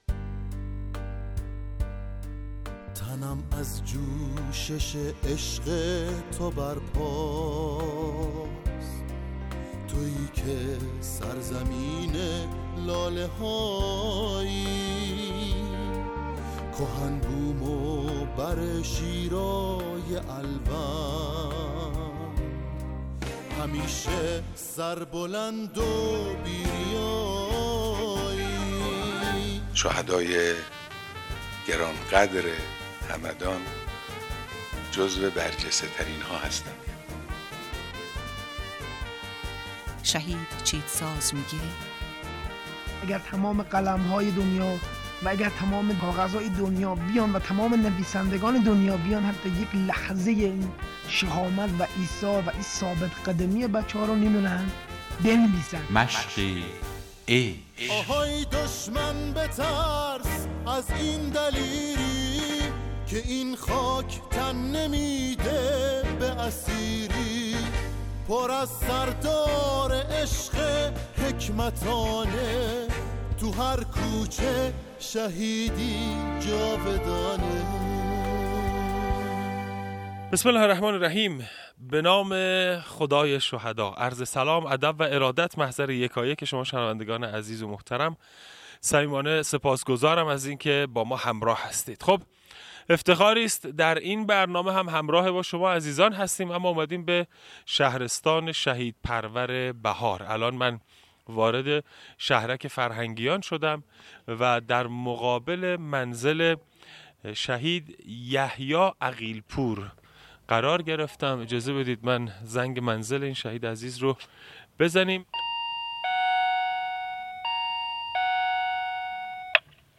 مصاحبه صوتی